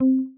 conf_mute.wav